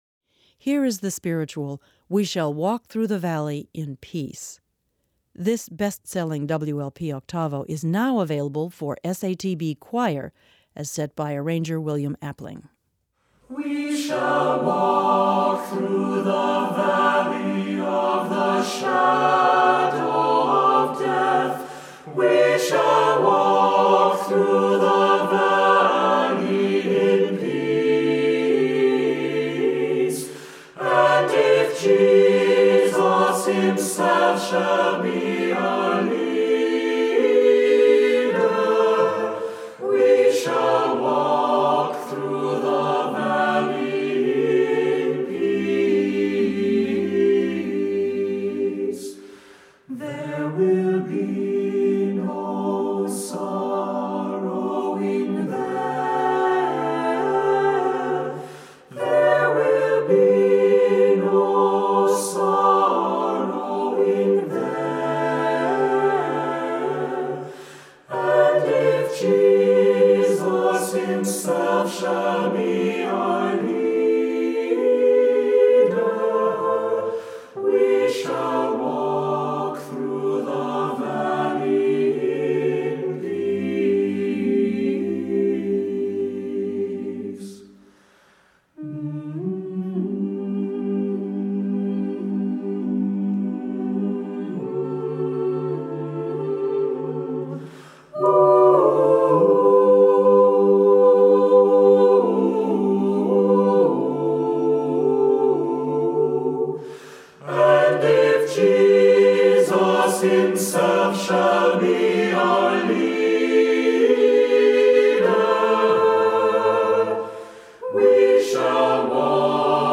Voicing: a cappella,SATB